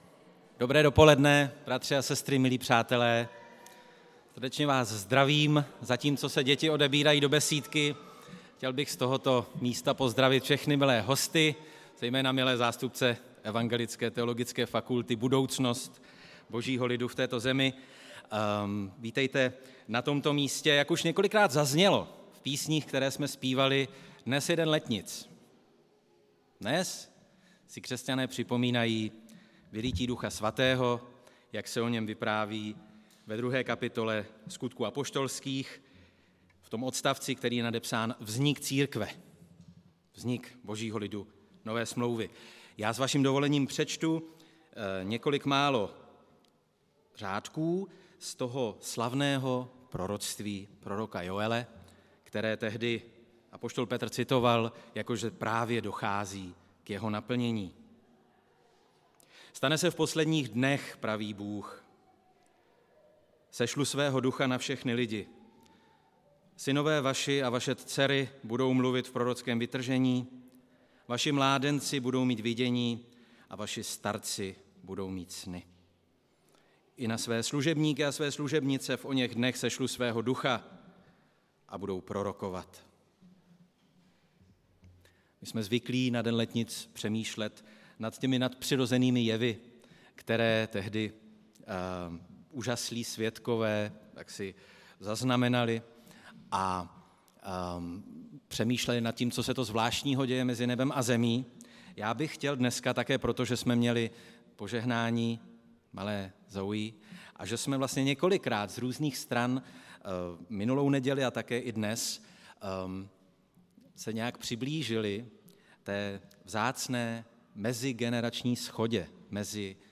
Událost: Kázání